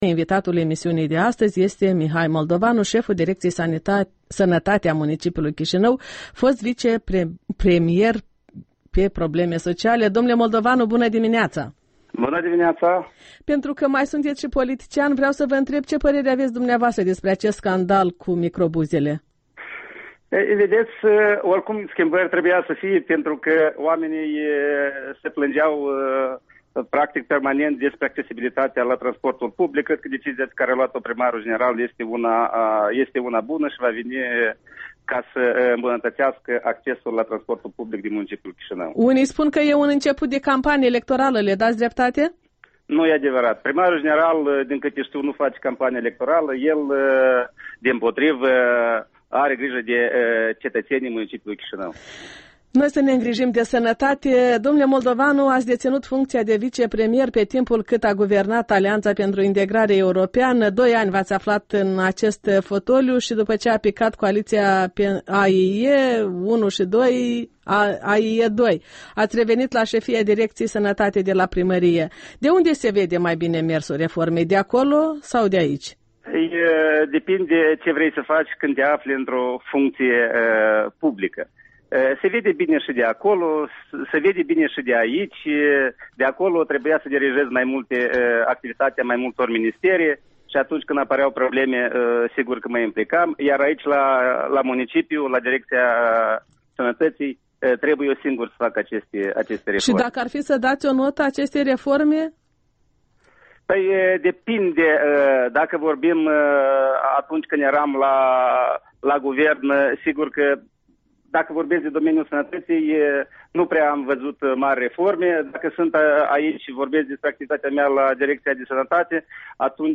Interviu dimineții cu șeful Direcției Sănătate a municipiului Chișinău.
Interviul dimineții cu Dr. Mihai Moldovanu